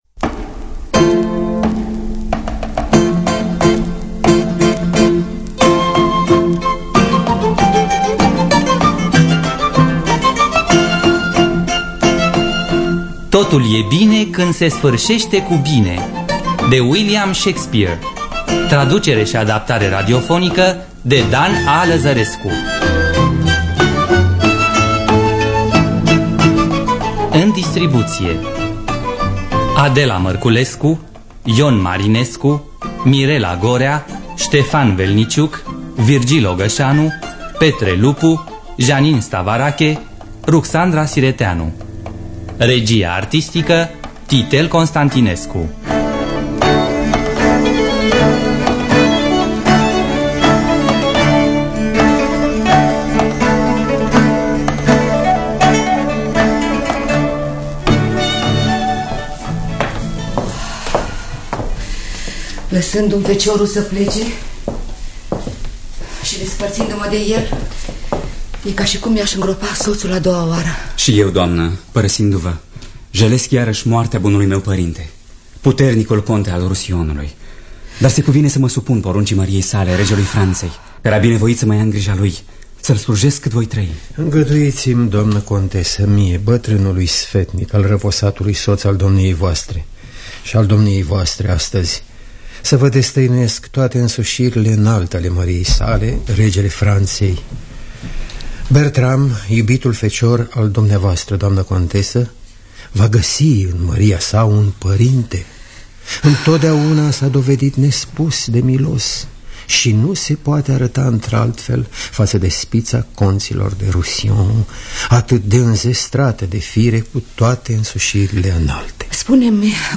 Totul e bine când se sfârșește cu bine de William Shakespeare – Teatru Radiofonic Online
Înregistrare din anul 1987 (22 noiembrie).